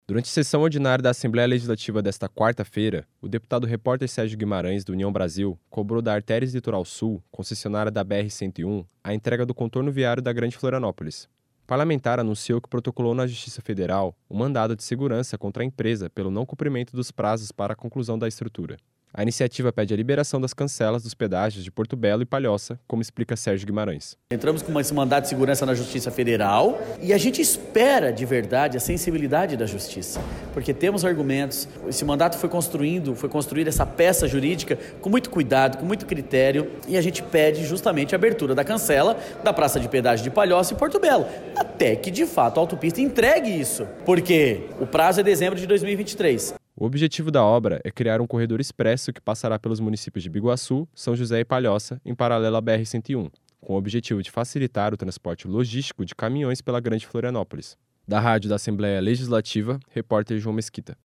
Durante a sessão ordinária da Assembleia Legislativa desta quarta-feira (13), o deputado Repórter Sérgio Guimarães (União) cobrou da Arteris Litoral Sul, concessionária da BR-101, a entrega do Contorno Viário da Grande Florianópolis.
Entrevista com:
- deputado Repórter Sérgio Guimarães (União).